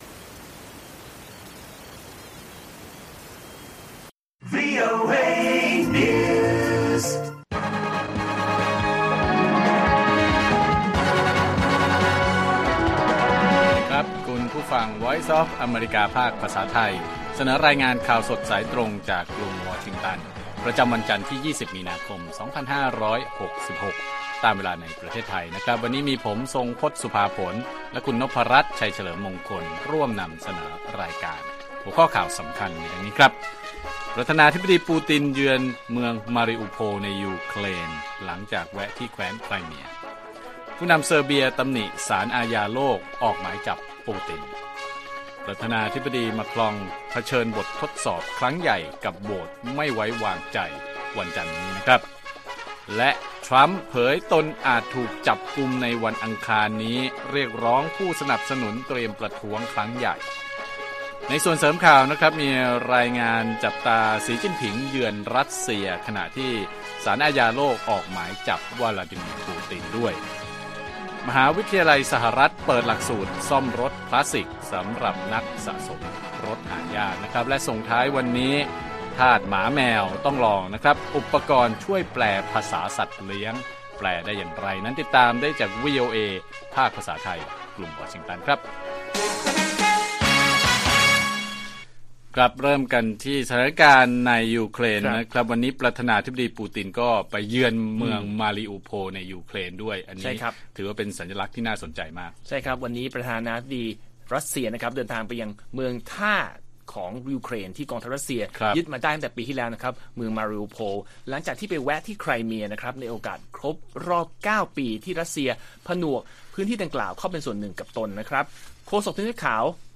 ข่าวสดสายตรงจากวีโอเอไทย 6:30 – 7:00 น. วันที่ 20 มี.ค. 2566